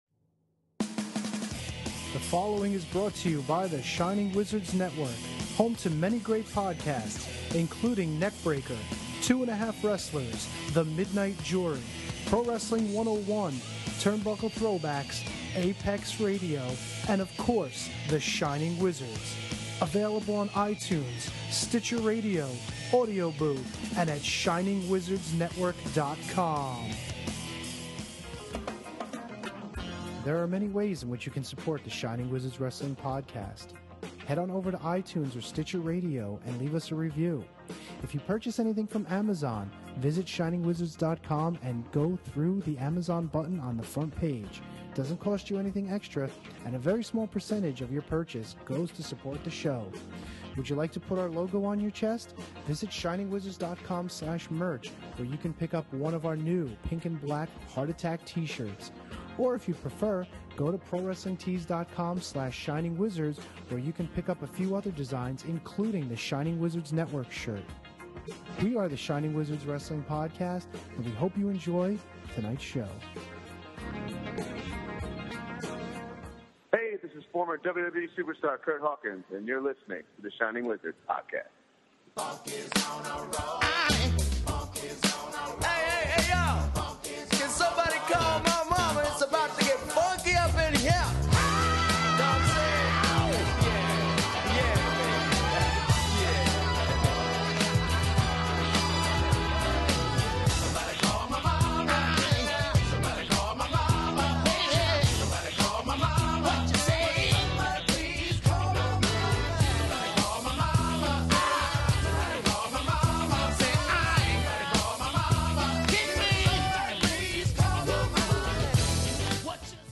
The boys are in studio to talk Impact, Money in the Bank, and Raw.